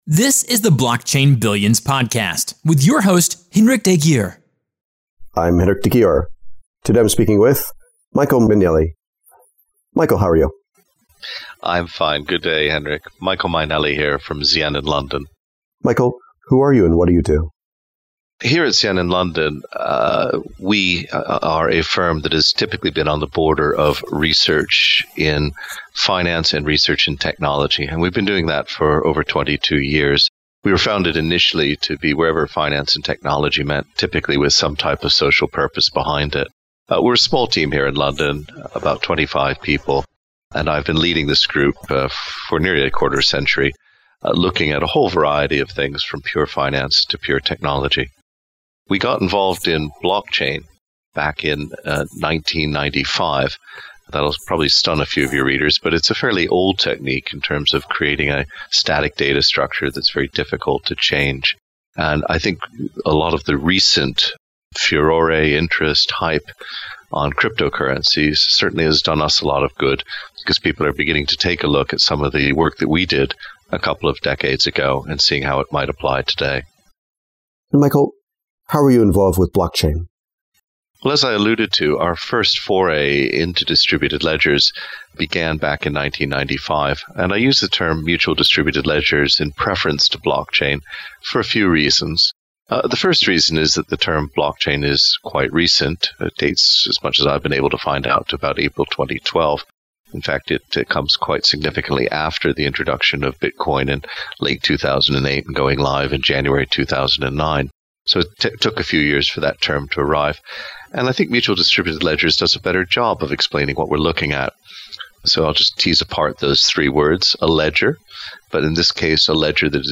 blockchain-billions-interview-with-michael-mainelli.mp3